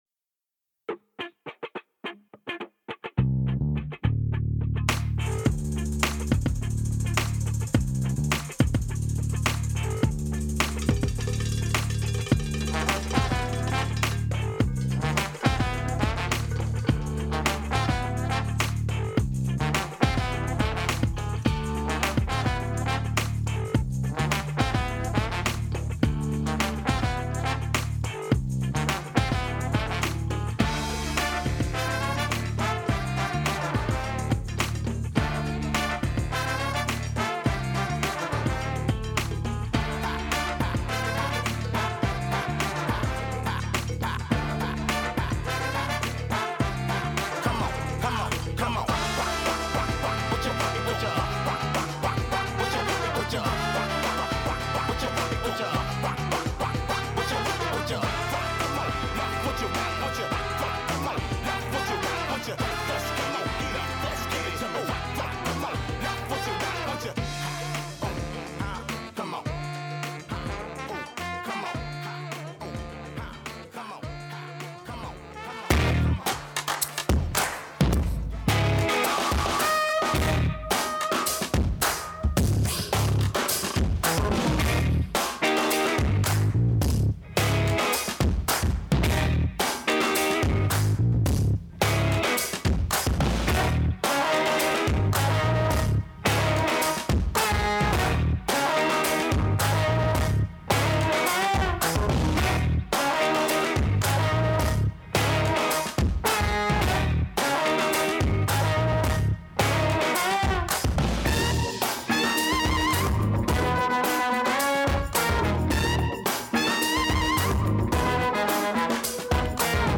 This is just a bit of jazz, and an excellent bit of it too.
There’s a good amount of soul/R&B thrown in, which took me a while to settle into.